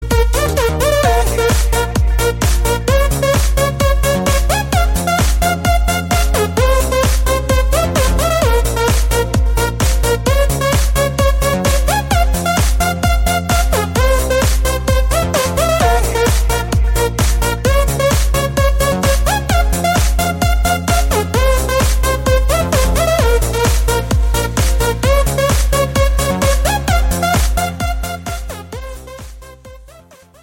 • Качество: 320, Stereo
громкие
заводные
electro house
звонкие